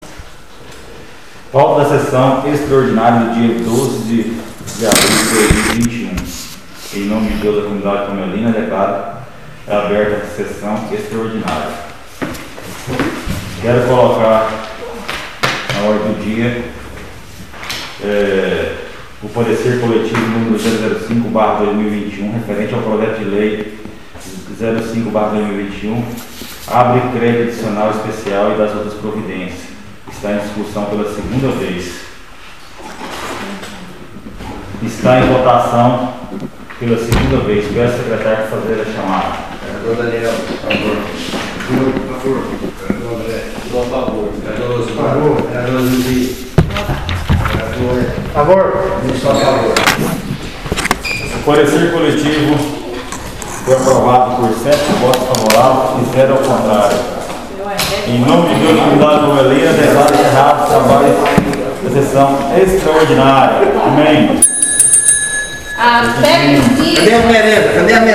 SESSÃO EXTRAORDINÁRIA DIA 12/04/2021 — Câmara Municipal de Palmelo